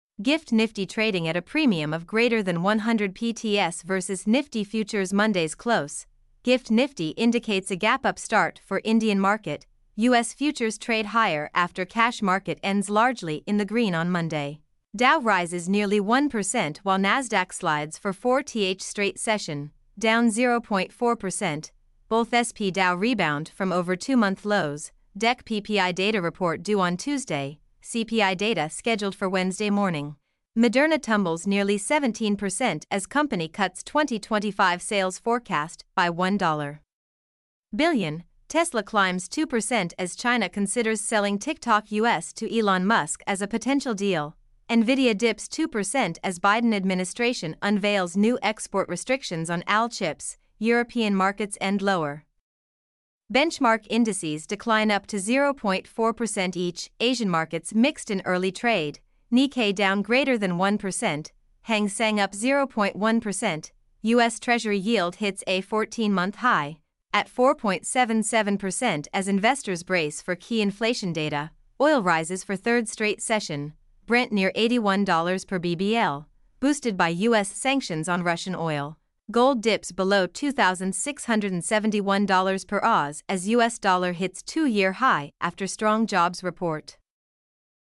mp3-output-ttsfreedotcom4.mp3